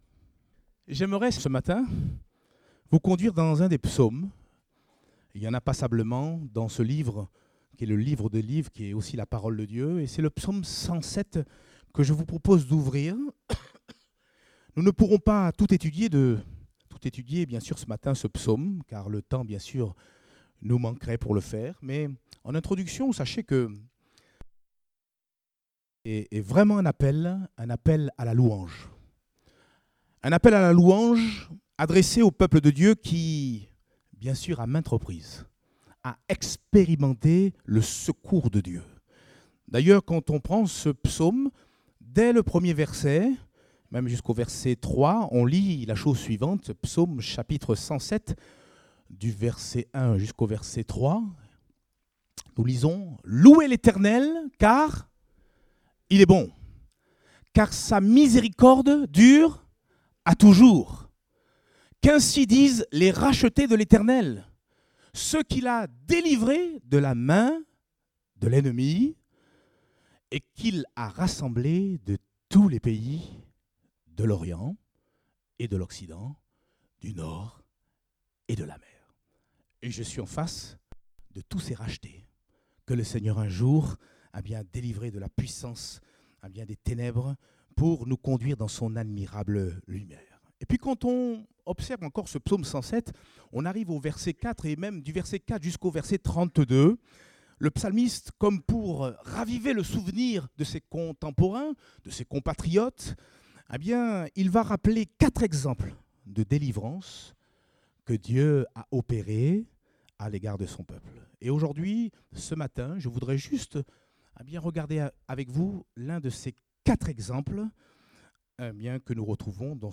Date : 14 janvier 2018 (Culte Dominical)